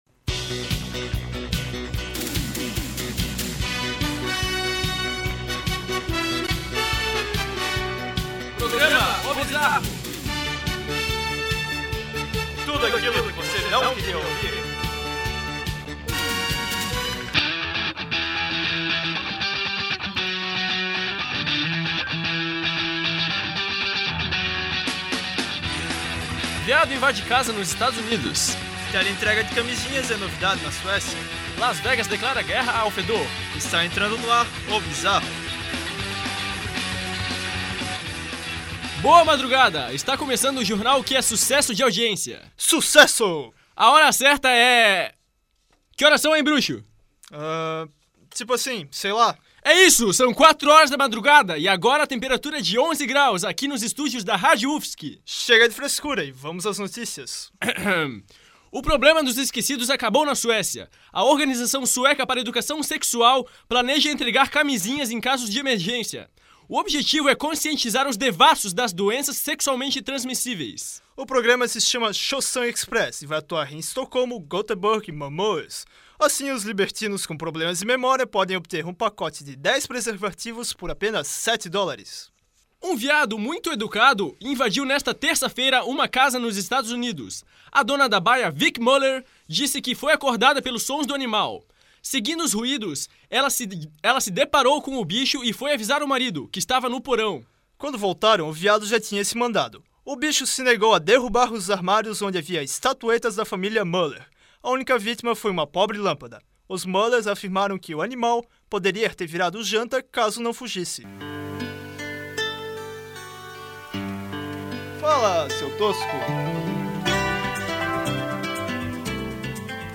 Abstract: Notícias estranhas, fatos curiosos e locutores bizarros.